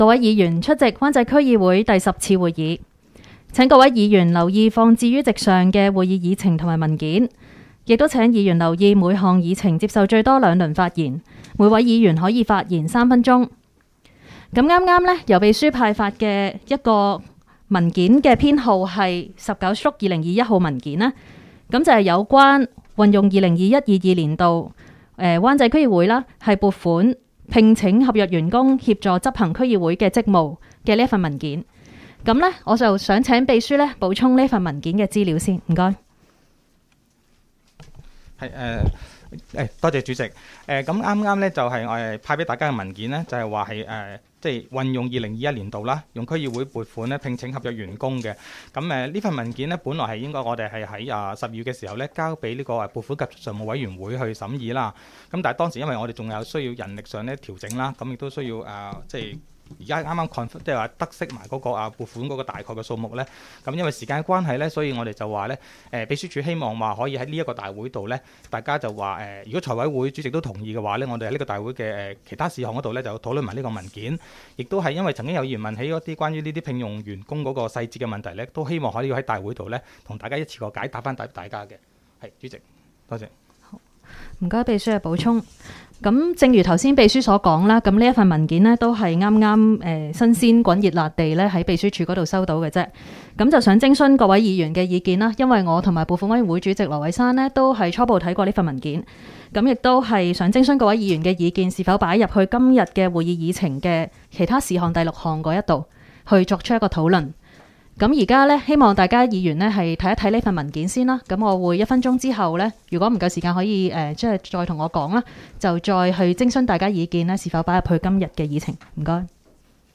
区议会大会的录音记录
湾仔区议会第十次会议
湾仔民政事务处区议会会议室